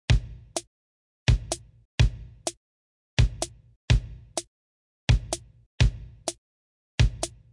描述：鼓机敲鼓。
Tag: 节奏 鼓声 打击乐循环 节奏